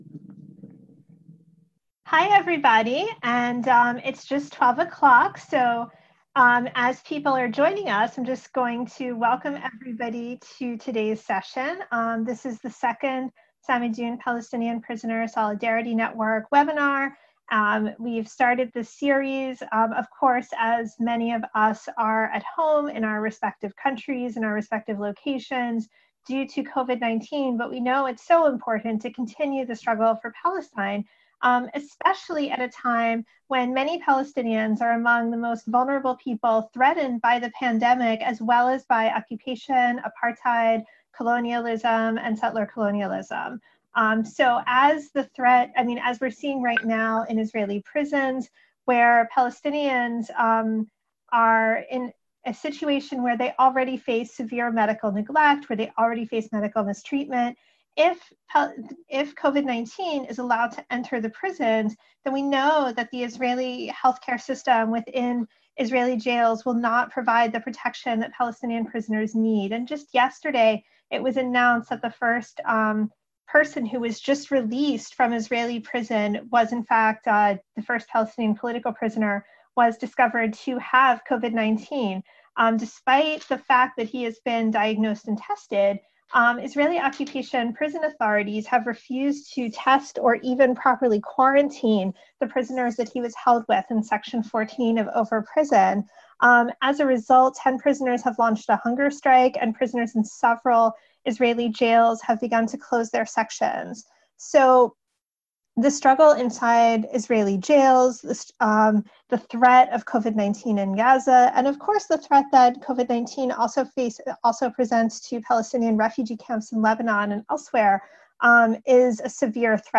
Video: Webinar on Palestinian refugees and the right of return